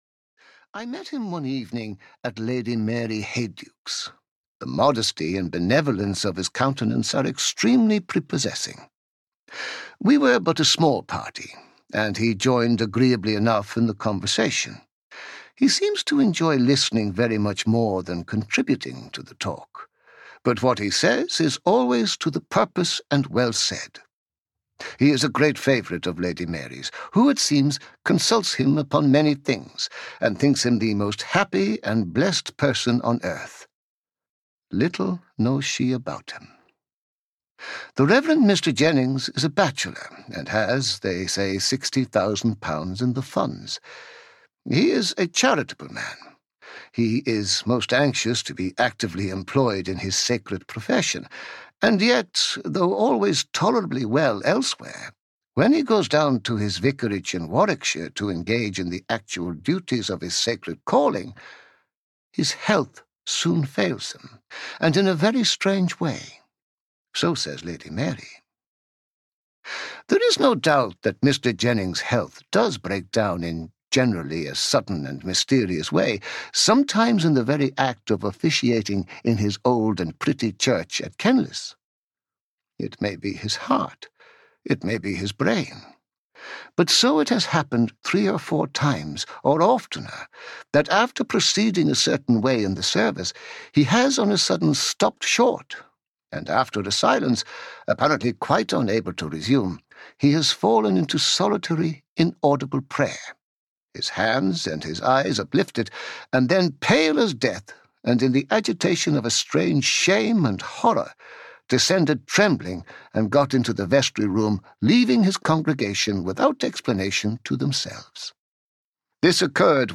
Green Tea (EN) audiokniha
Ukázka z knihy